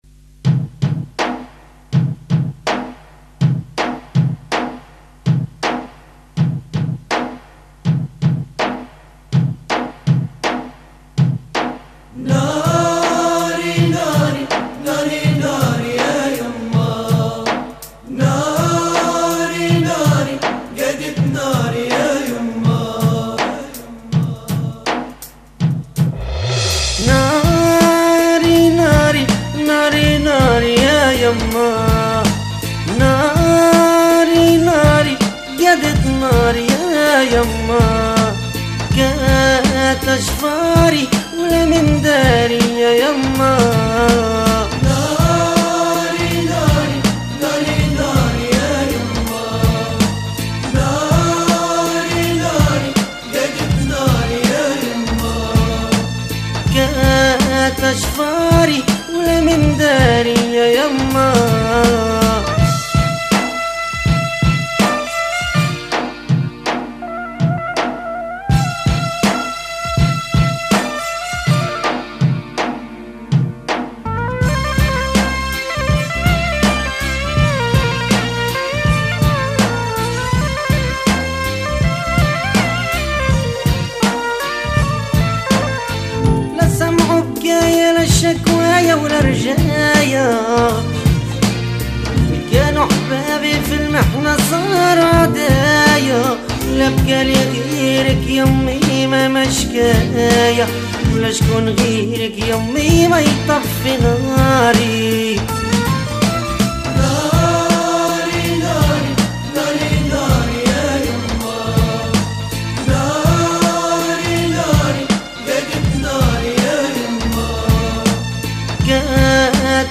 Bienvenue au site des amateurs de Mezoued Tunisien
la chanson